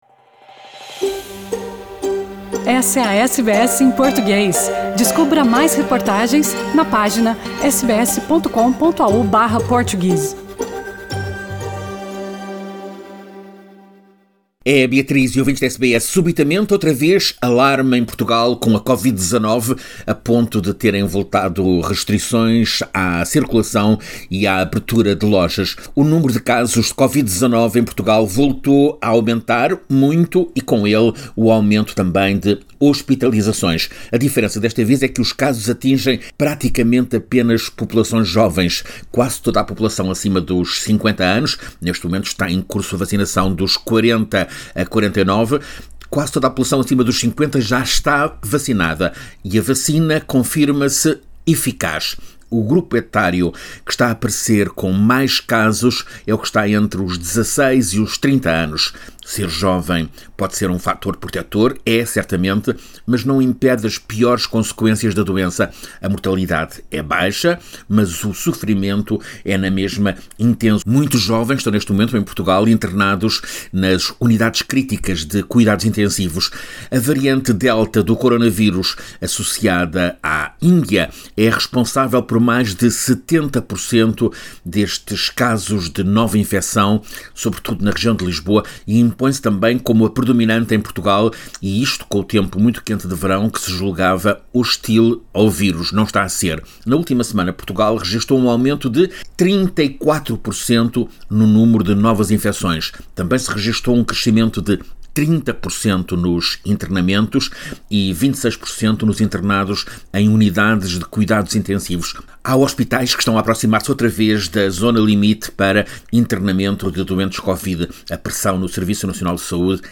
crônica